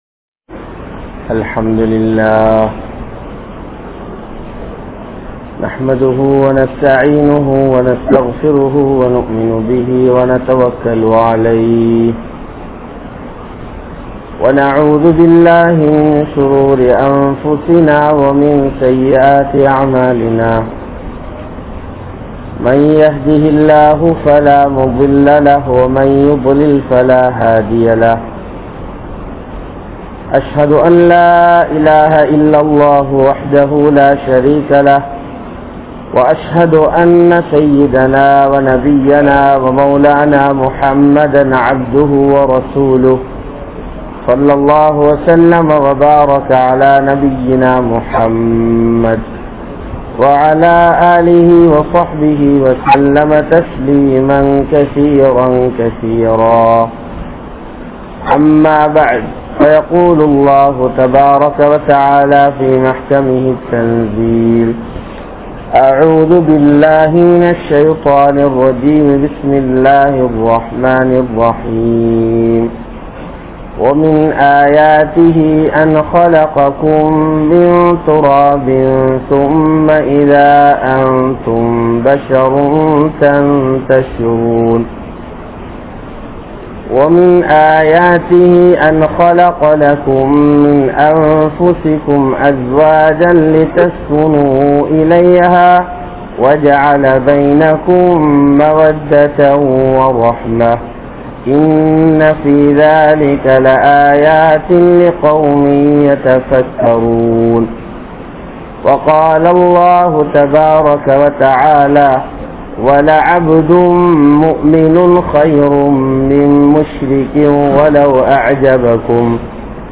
Nikkah Seivathan Noakkam (நிக்காஹ் செய்வதன் நோக்கம்) | Audio Bayans | All Ceylon Muslim Youth Community | Addalaichenai
Akbar Town Jumua Masjidh